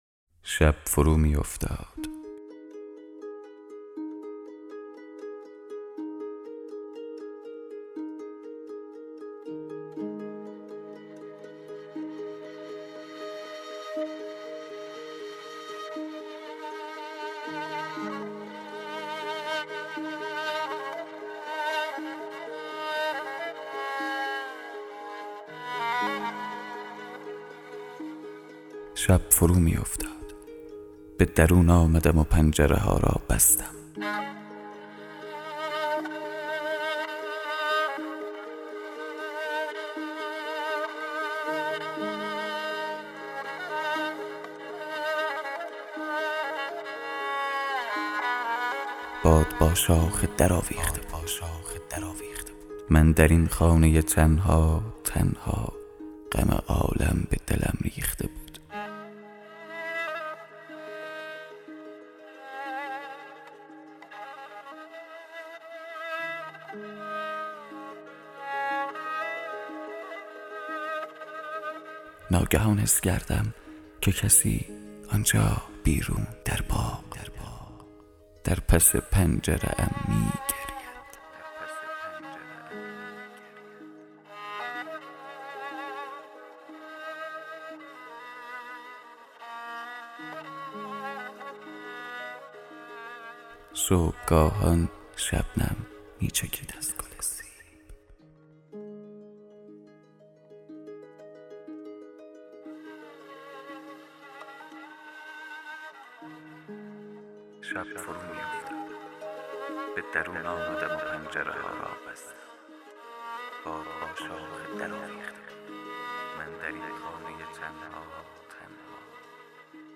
دکلمه